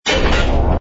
engine_br_freighter_start.wav